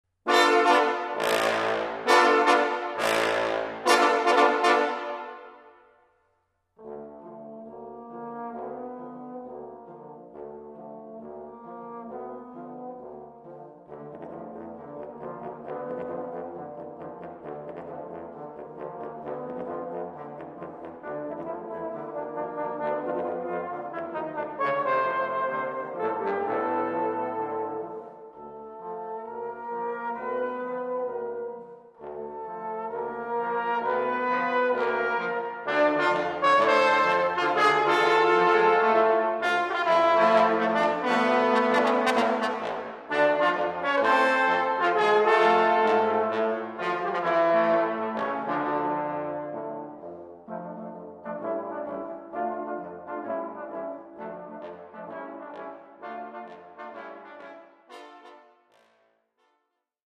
Gattung: Posaunenquartett
Besetzung: Instrumentalnoten für Posaune